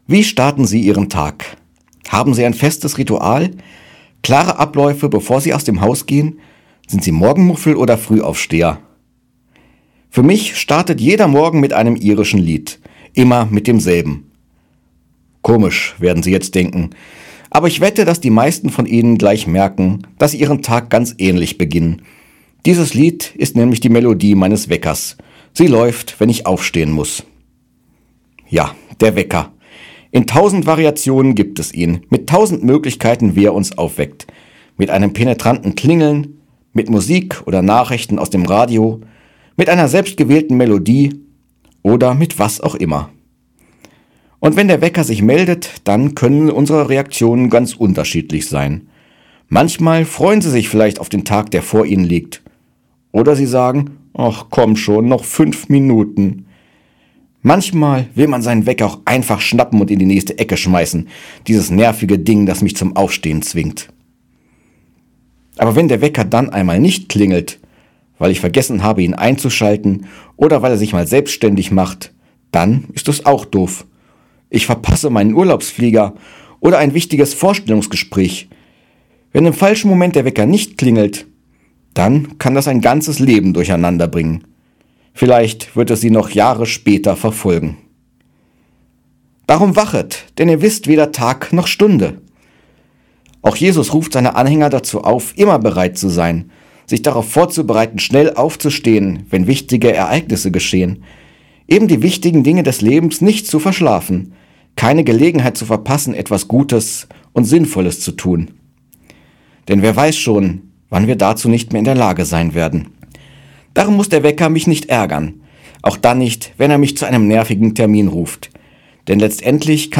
Radioandacht vom 4. Dezember